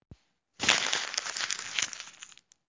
描述：弄皱纸浪费
Tag: 揉碎 废纸